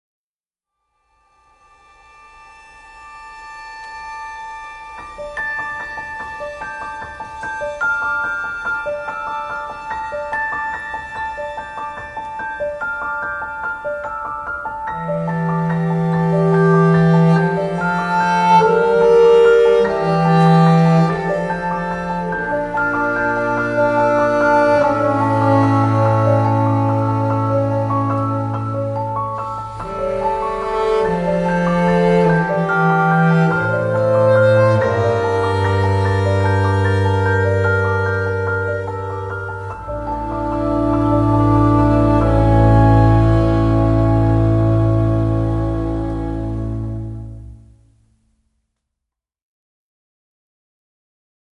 vibrato-less string solos